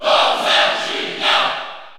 Crowd cheers (SSBU) You cannot overwrite this file.
Bowser_Jr._Cheer_French_PAL_SSBU.ogg